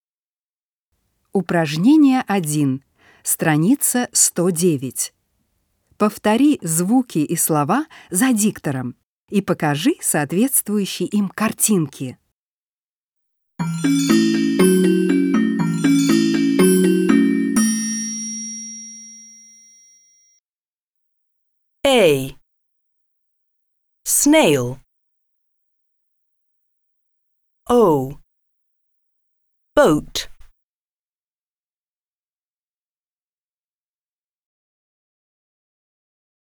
1. Повтори звуки и слова за диктором и покажи соответствующие им картинки.